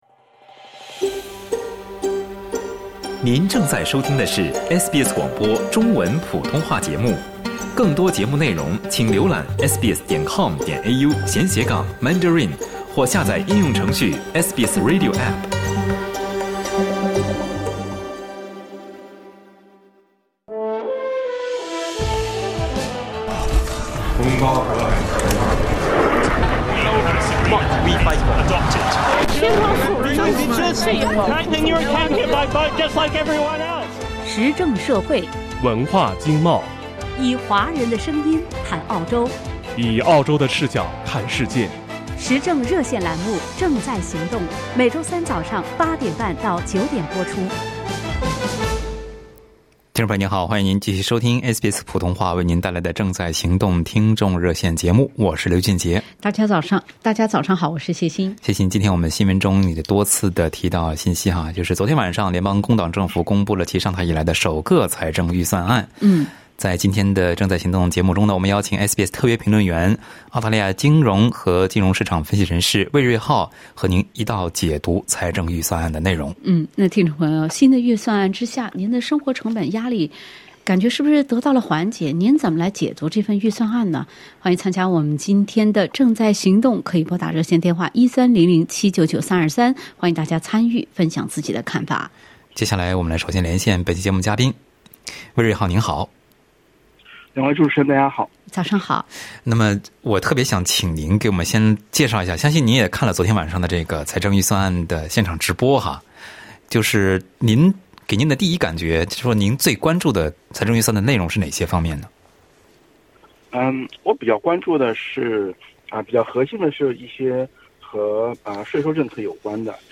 在节目中，听友们也通过热线表达了对财政预算的看法。